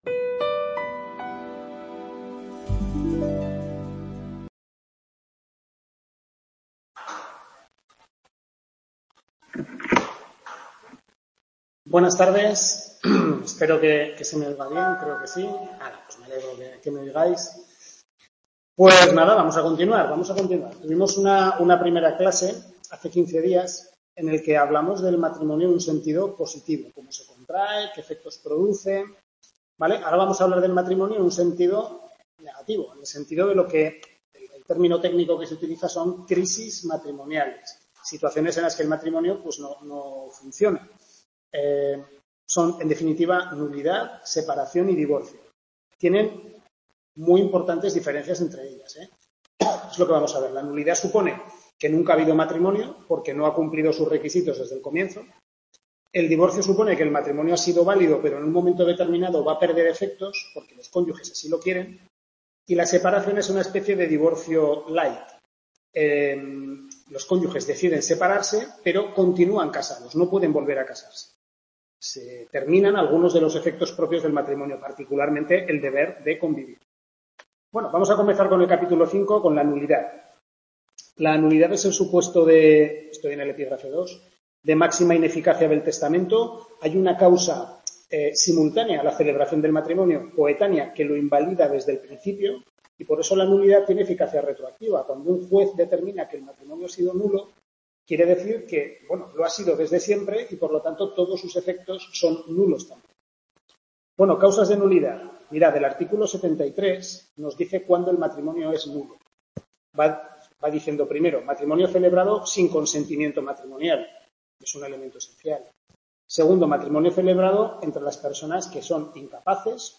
Segunda tutoría de Civil I (derecho de Familia), centro de Calatayud, capítulos 5-8 del Manual del Profesor Lasarte